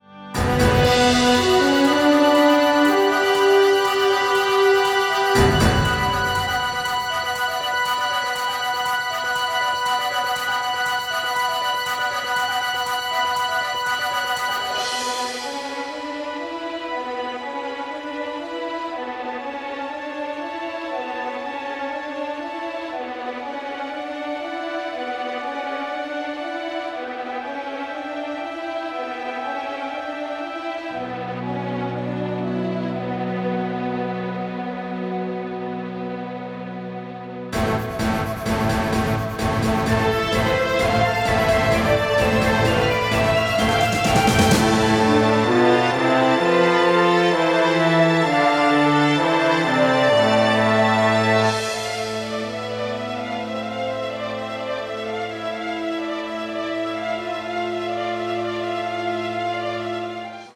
entirely composed using electronic elements